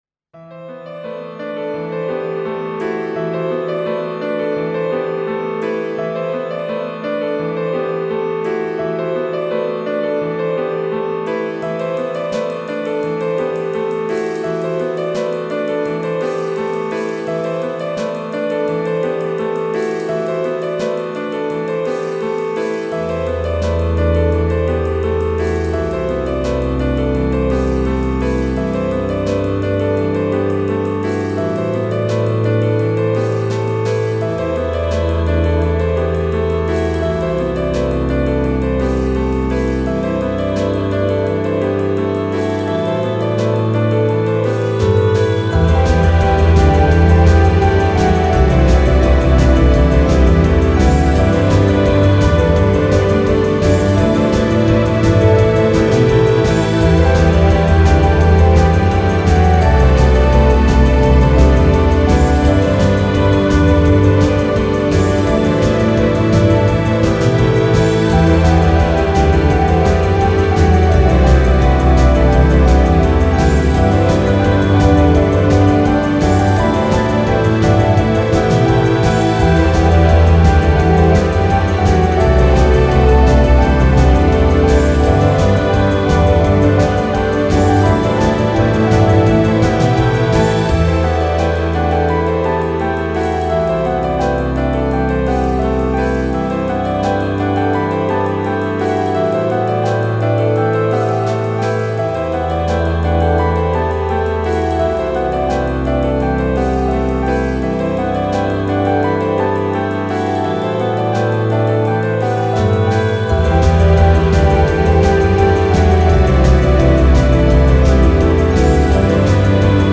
Piano Rock